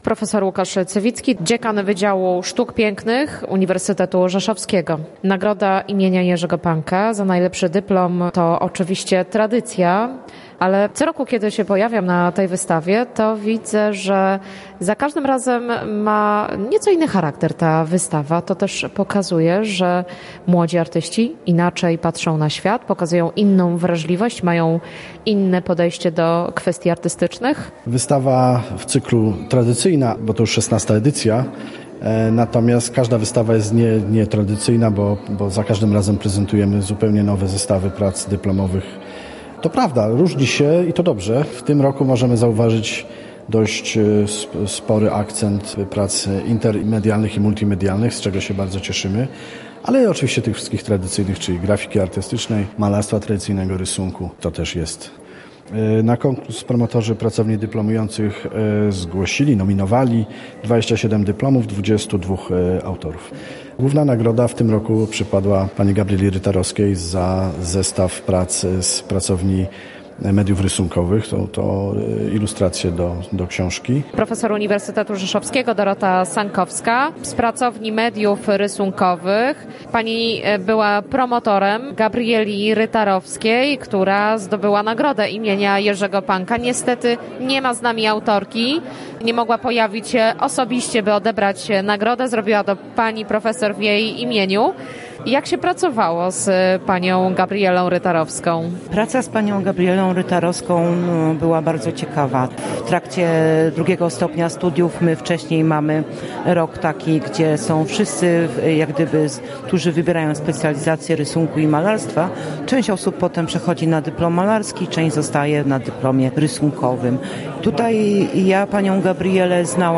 Relację z uroczystego wręczenia nagród